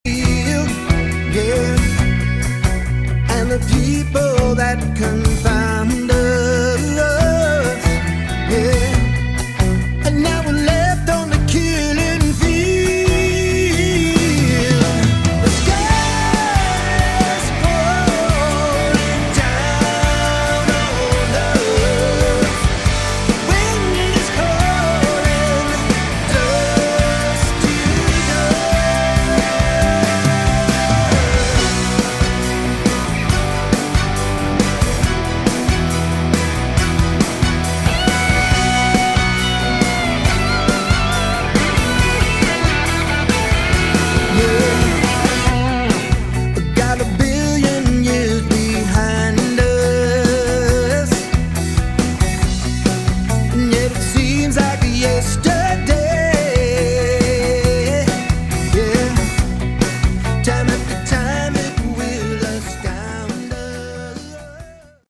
Category: Melodic Rock
vocals, keyboards, guitar
guitar, bass, keyboards
drums
Modern (pop) rock.
Quite laid back at times.